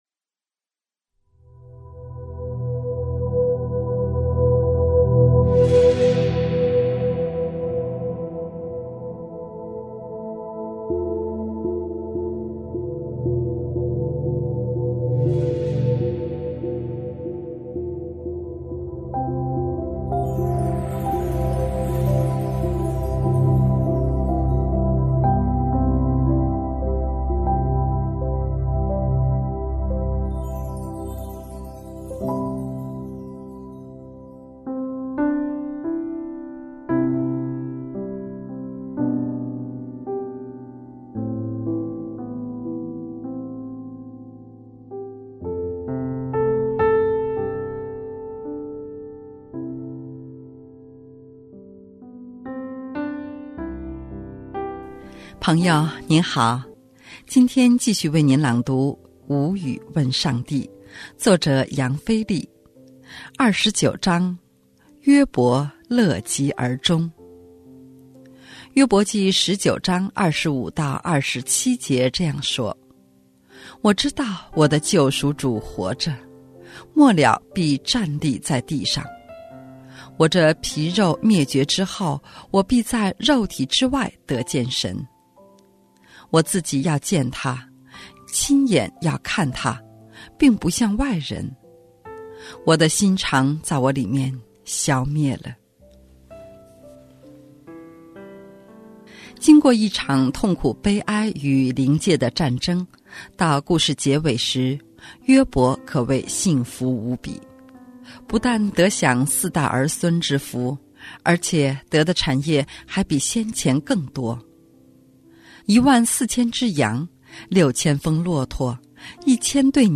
朋友您好，今天继续为你朗读《无语问上帝》。